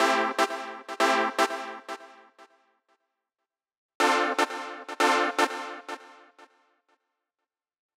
29 Synth PT3.wav